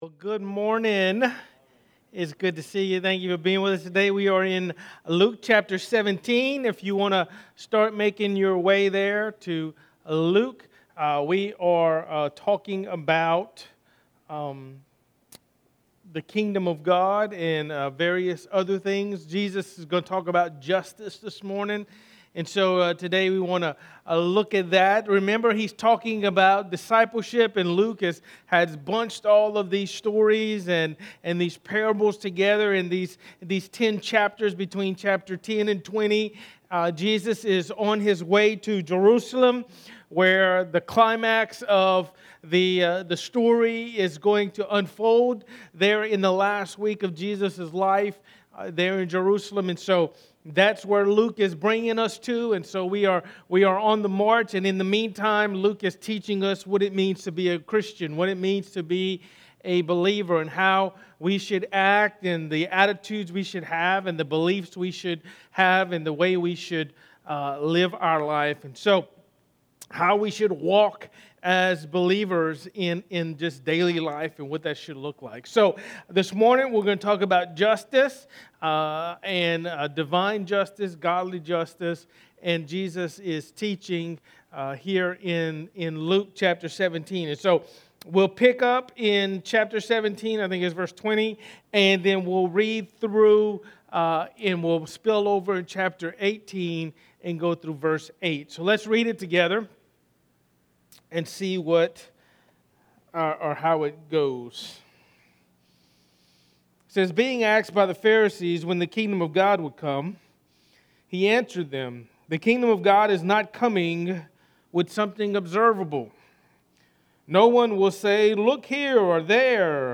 A message from the series "Luke." Jesus talks about the kingdom and the Day of the Lord.